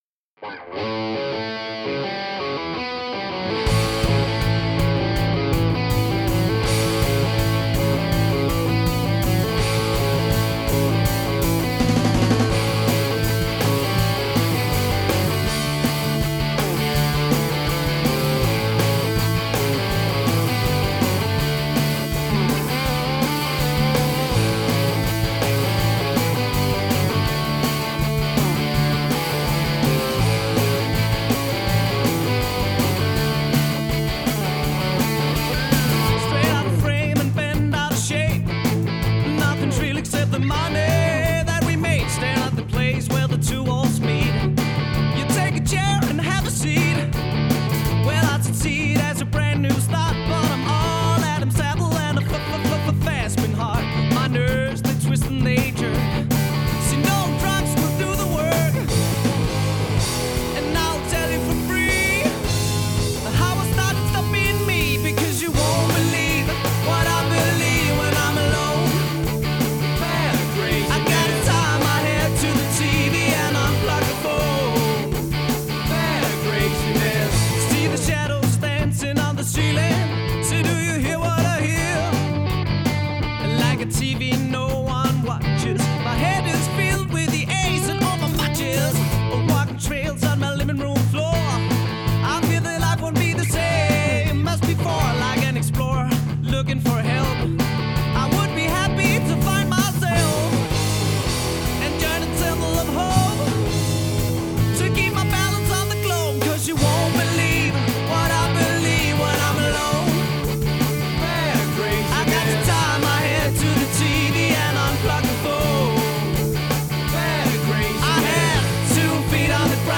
Energisk festmusik til enhver fest!
• Coverband
• Rockband